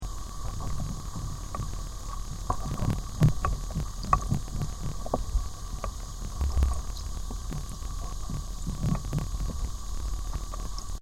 川の学習素材 川の音
そして、その川の流れに応じて川の中から聞こえてくる水中音も違います。 マイクを通して聞こえてくる音は、水流の音、川の外から水中を伝わってくる音、水流がマイクの表面を振動させて生じる音、そして大小の石や砂が河床を転がる音などがオーケストラのように重なっています。そのため、プールに潜ったときに皆さんが水中で聞く音とは違った音が川の中では聞こえてきます。